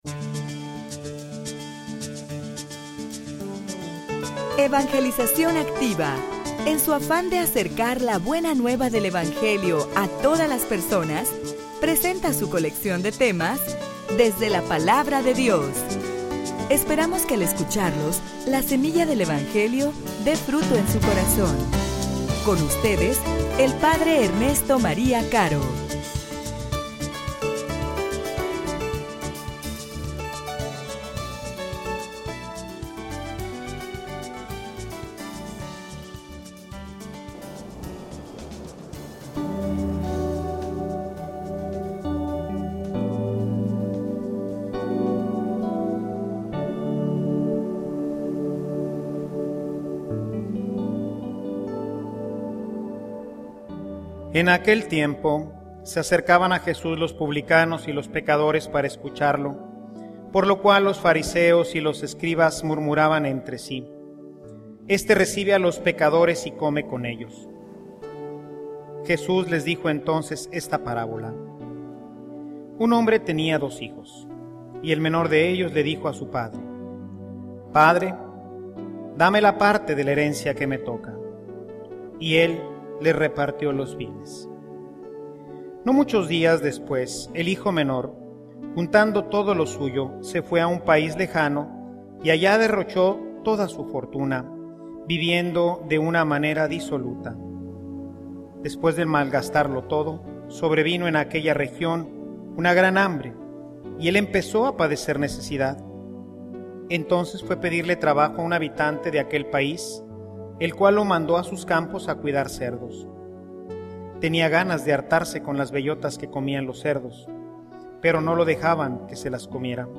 homilia_Crucifica_tu_carne.mp3